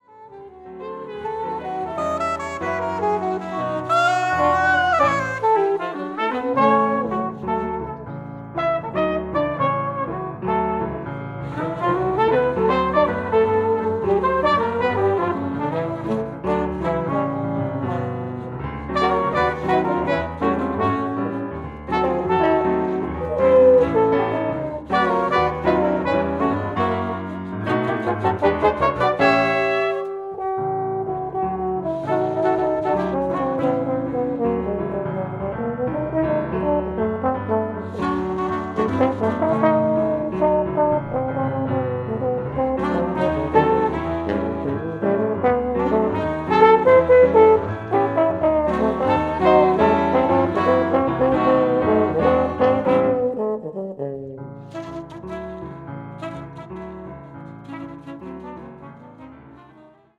pno, comp
trp
sax
trb